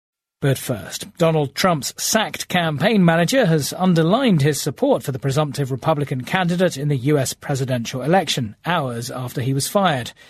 【英音模仿秀】特朗普开除竞选经理 听力文件下载—在线英语听力室